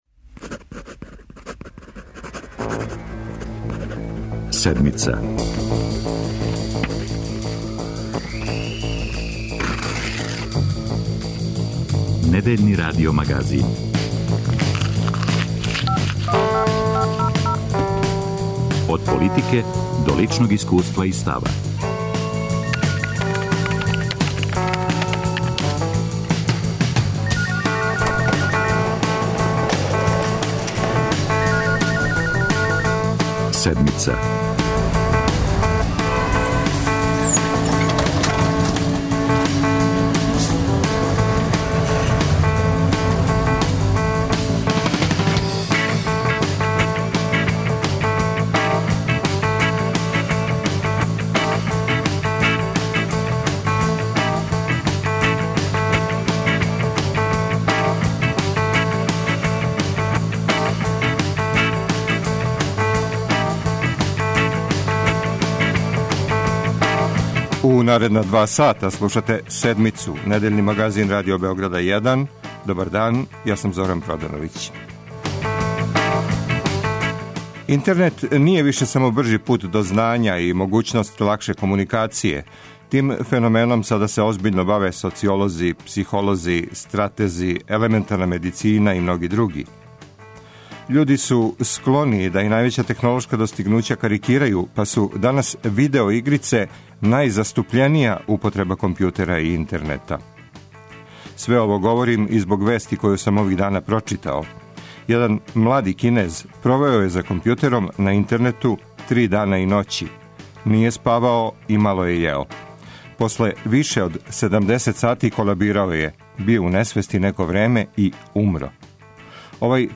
Из понуде домаћих прича издвојили смо две, тему о бахатости локалних моћника у Кули и репортажу о брзом упознавању (speed dating) - посредовању у упознавању будућих брачних парова.